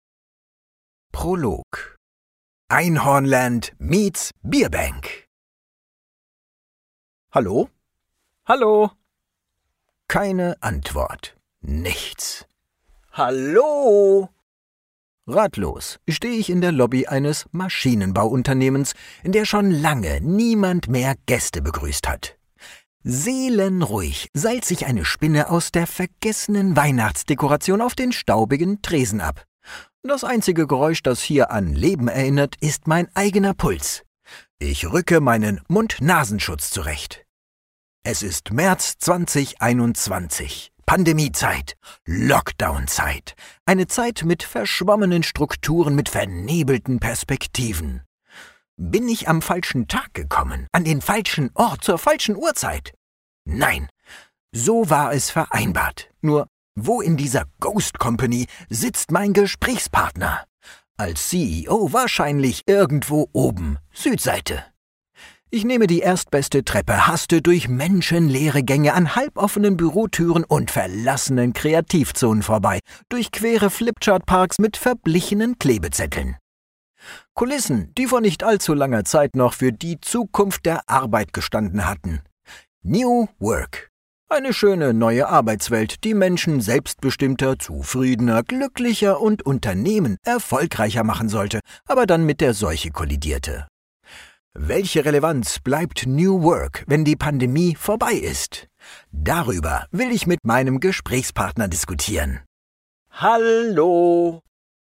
Male
Spanish (Latin American)
Narration